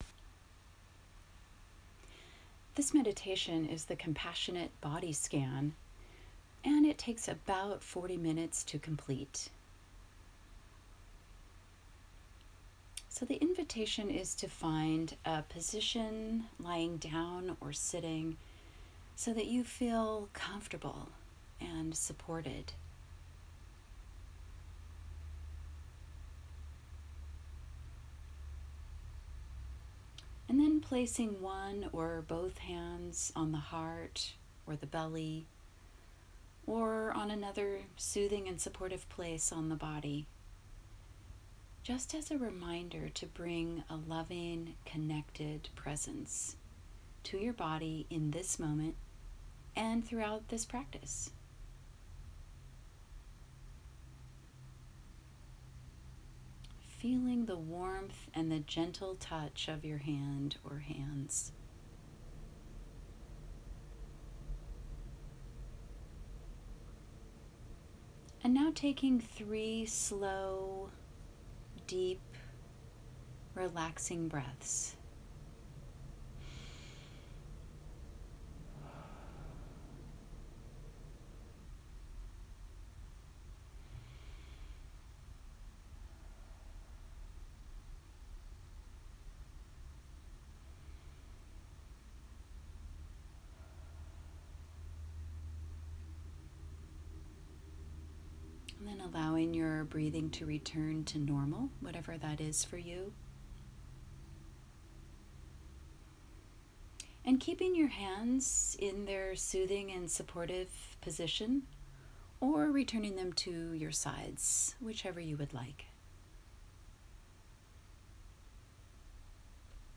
Compassionate Body Scan - 38 minutes